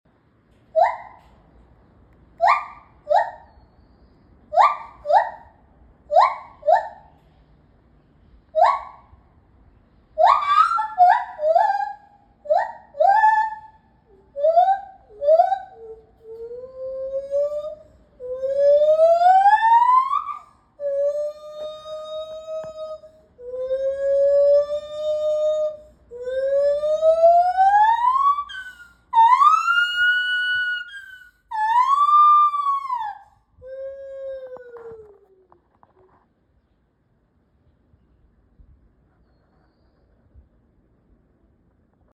シロテテナガザル鳴き声 - データセット - オープンデータプラットフォーム | データカタログサイト